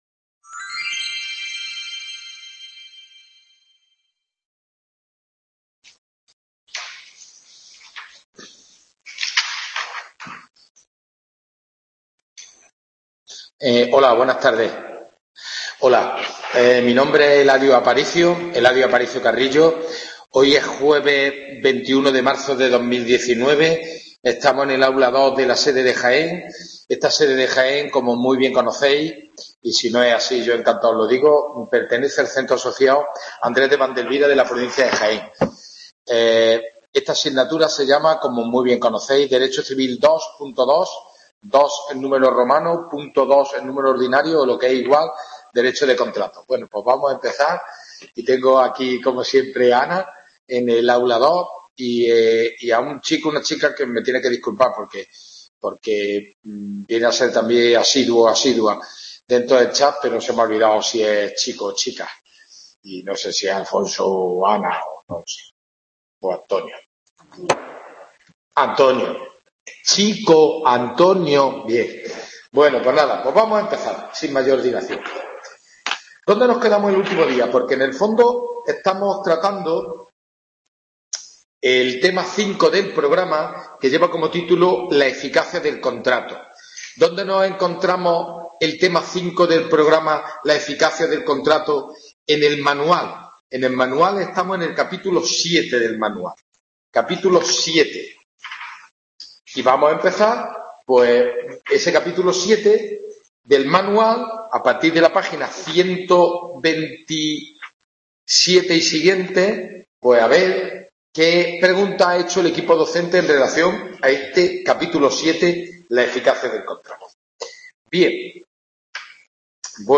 WEBCONFERENCIA